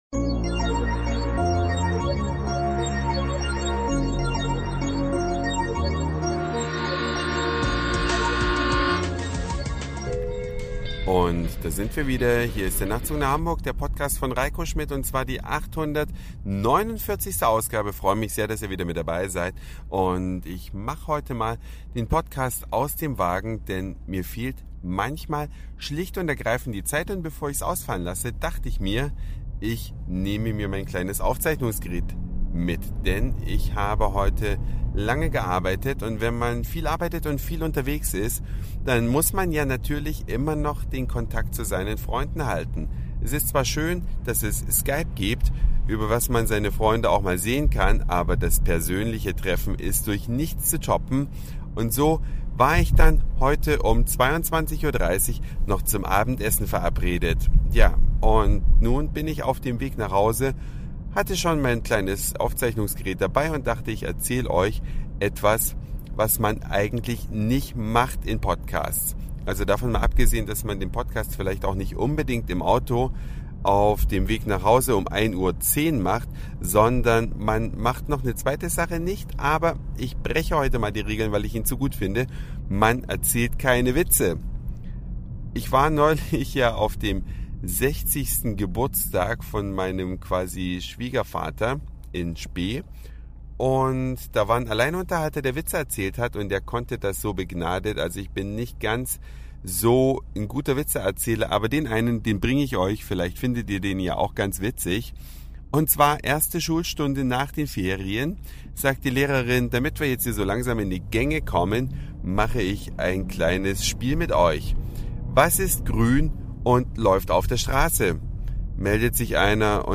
NnH849 Podcasten im Auto und Witz